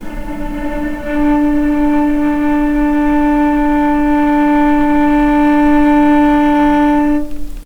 healing-soundscapes/Sound Banks/HSS_OP_Pack/Strings/cello/ord/vc-D4-pp.AIF at 01ef1558cb71fd5ac0c09b723e26d76a8e1b755c
vc-D4-pp.AIF